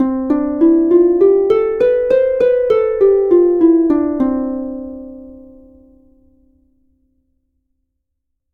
Escala jónica
arpa
sintetizador